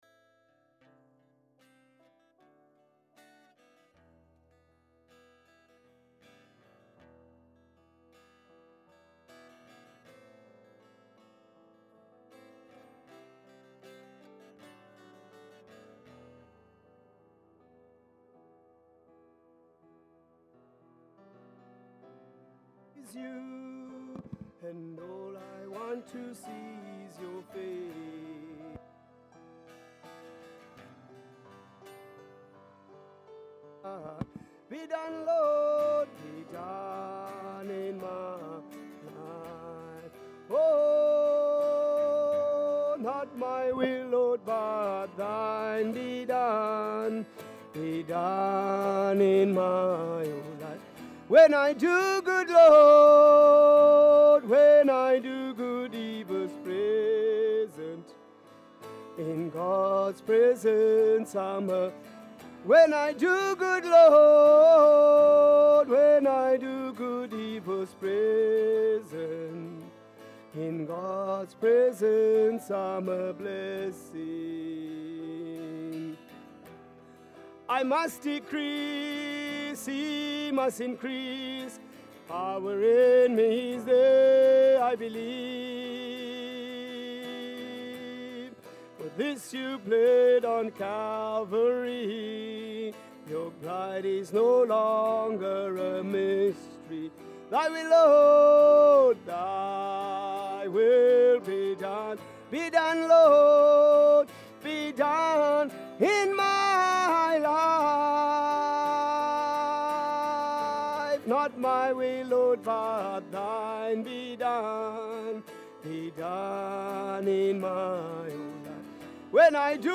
Church Services